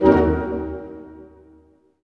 Index of /90_sSampleCDs/Roland L-CD702/VOL-1/HIT_Dynamic Orch/HIT_Tutti Hits